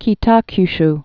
(kē-täky-sh)